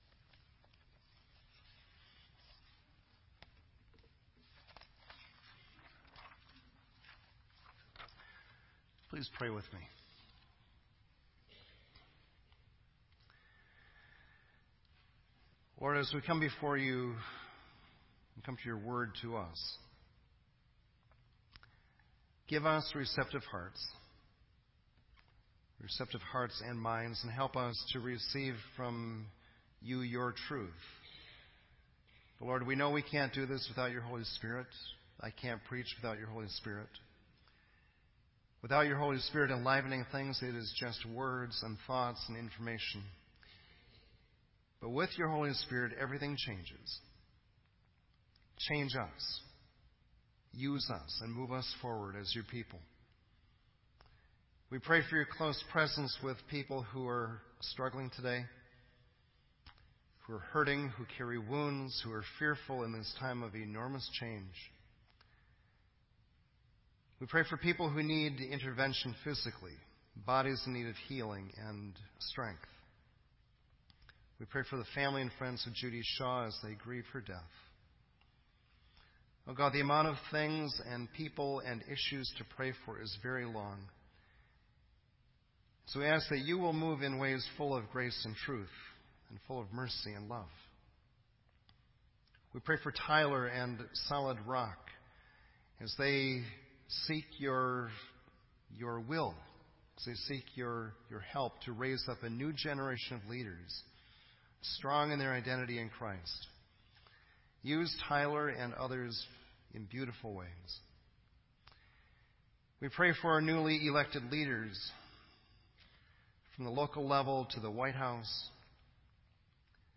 This entry was posted in Sermon Audio on November 14